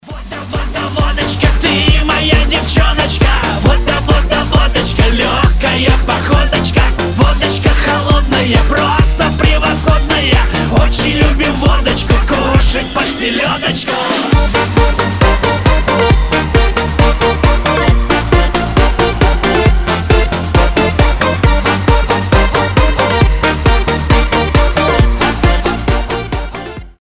• Качество: 320, Stereo
позитивные
веселые